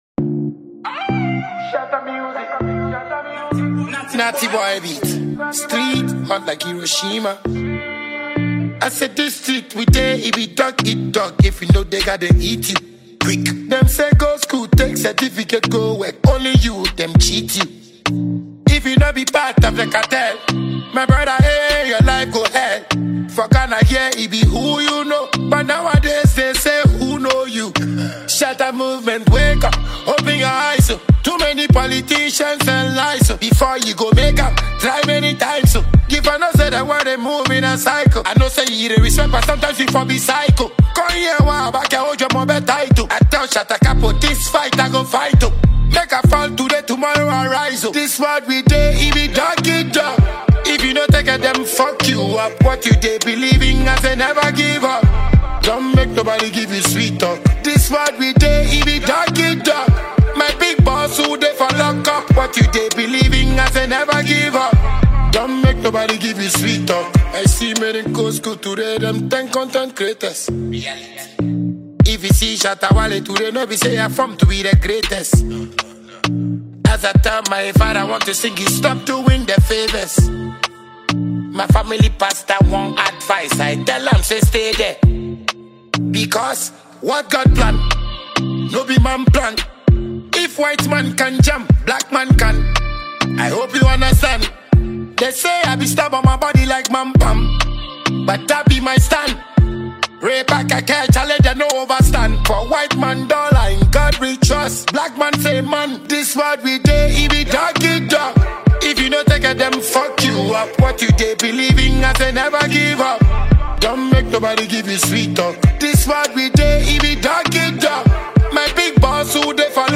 Self-acclaimed Ghanaian dancehall King